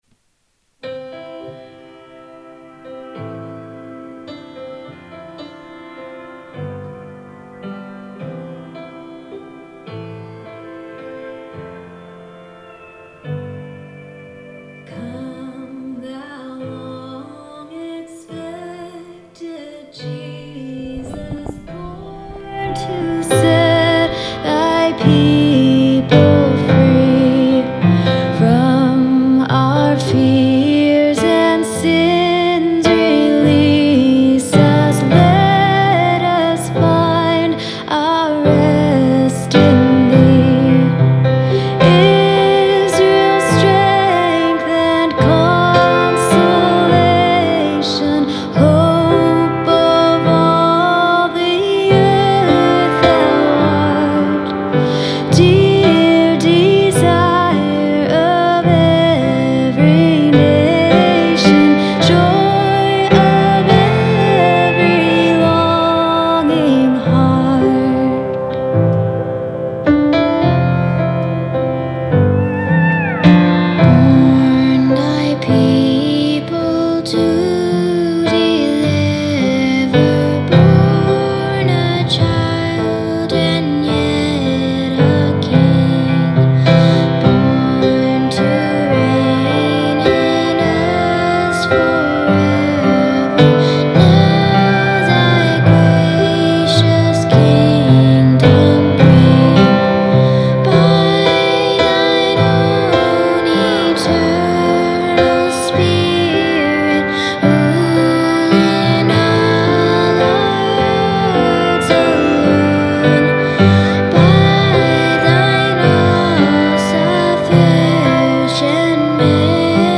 Hymn: Come, Thou Long Expected Jesus
Reading: Psalm 25: 1-10